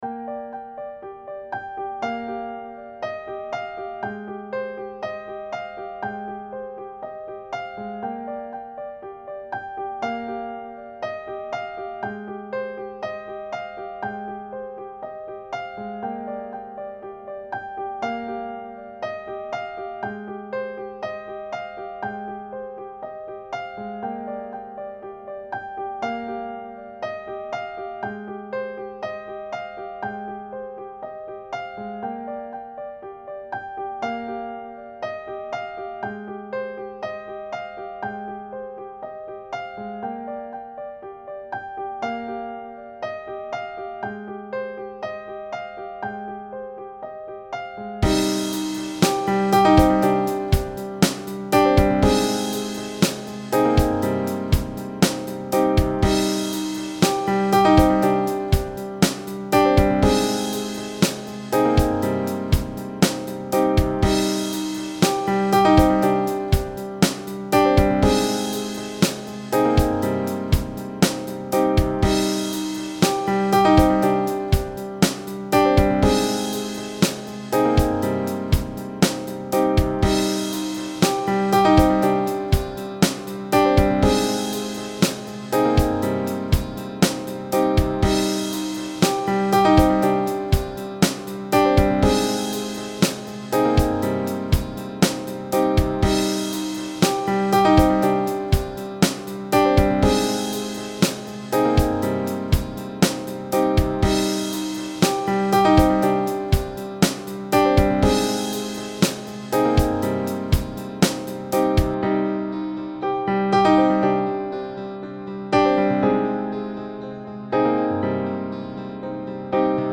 Also absent vocals, with a few corrections to be made.
such dynamic and moving piano. your hired